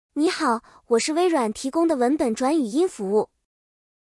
Edge-TTS是微软提供的在线文本转自然语音，支持多种语言和声音，转换速度快，语音自然无机械感。
同一段文字分别使用这几种声音转成语音文件对比：